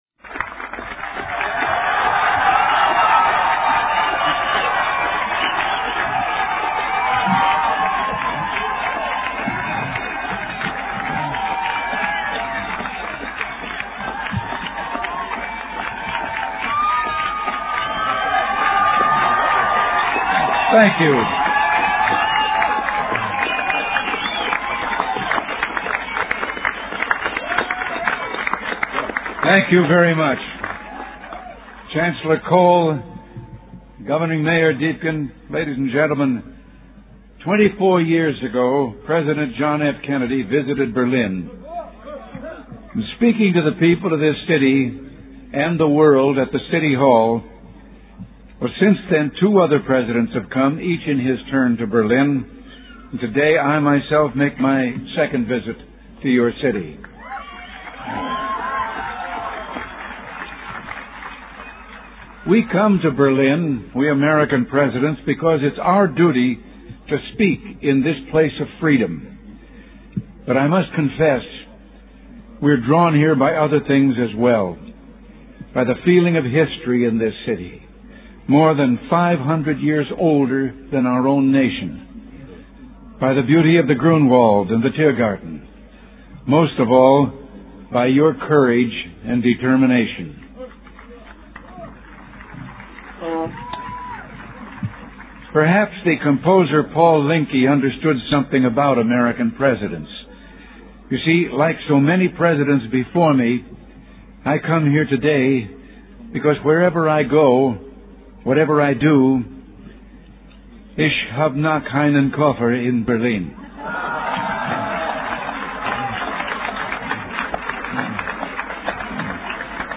delivered 12 June 1987, West Berlin